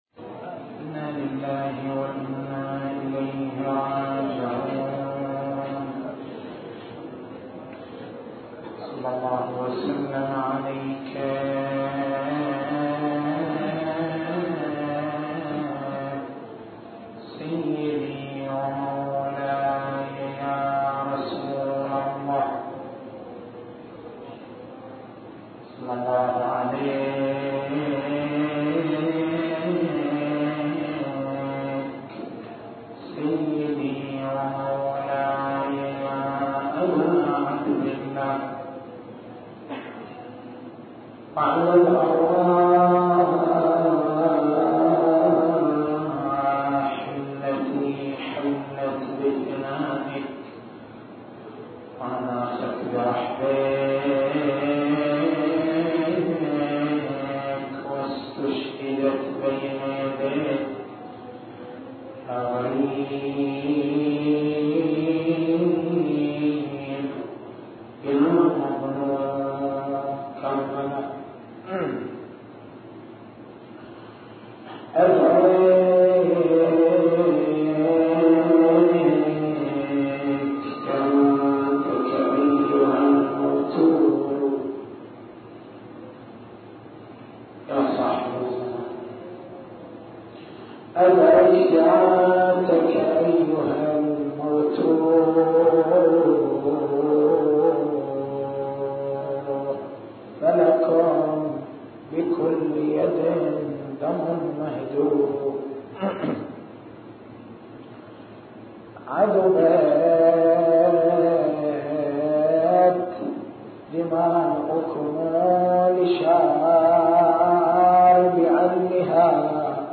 تاريخ المحاضرة: 12/01/1422 نقاط البحث: لمحات من سيرة السيدة زينب (ع) معالم الشخصية الزينبية ومقوّماتها دروسٌ من حياتها المباركة التسجيل الصوتي: تحميل التسجيل الصوتي: شبكة الضياء > مكتبة المحاضرات > محرم الحرام > محرم الحرام 1422